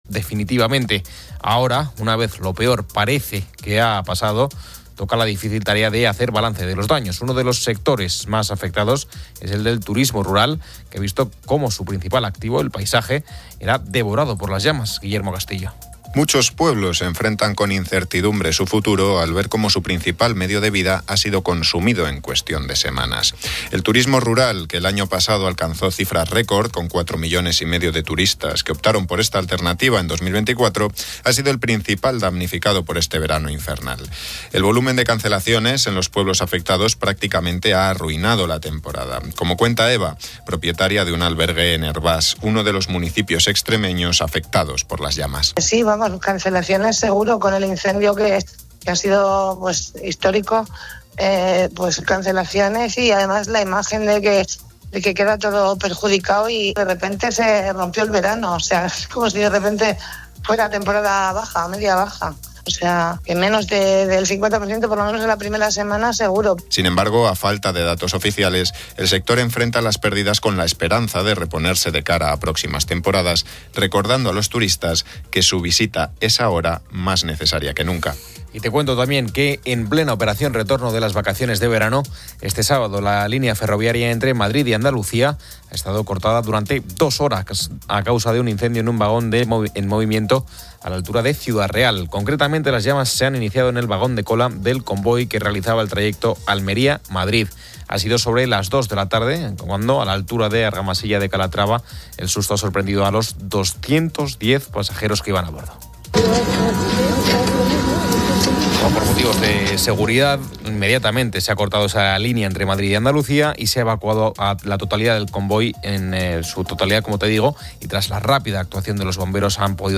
El programa continúa con la intervención de Antonio Lobato (IA) y una extensa entrevista con el humorista JJ Vaquero.